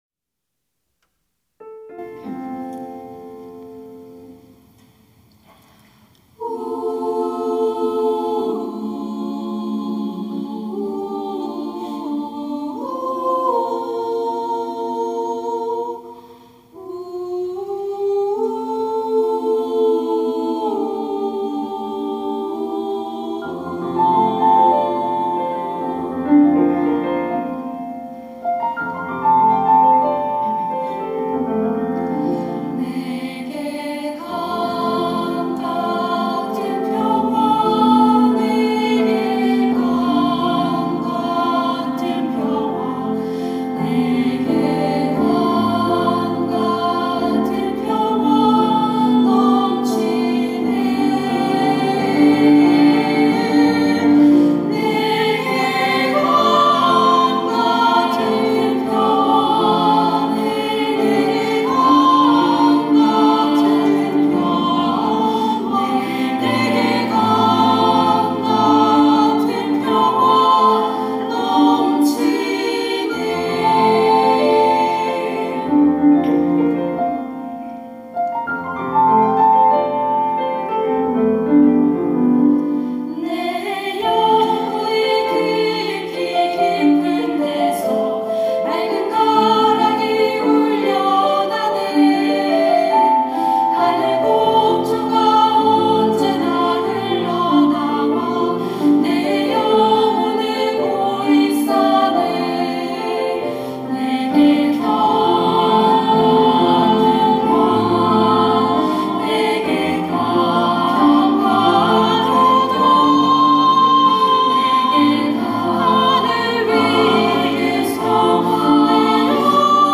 천안중앙교회
찬양대 글로리아